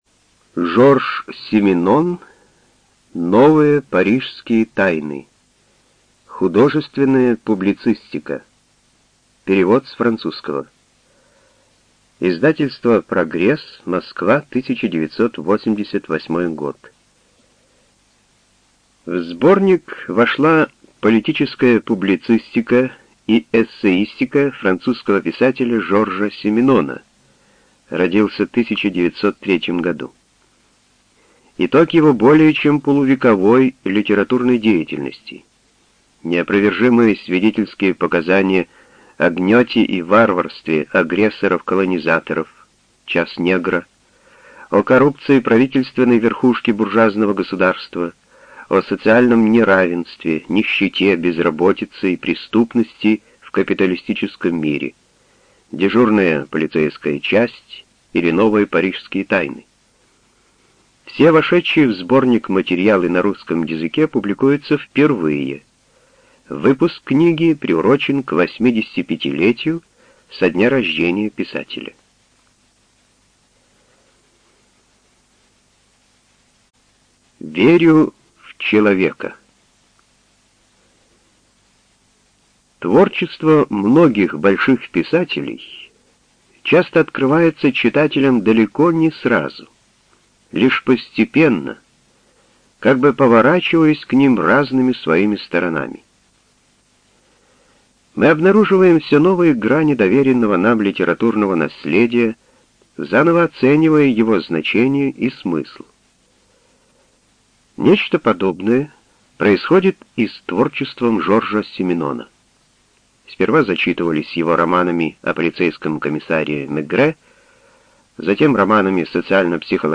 ЖанрПублицистика
Студия звукозаписиРеспубликанский дом звукозаписи и печати УТОС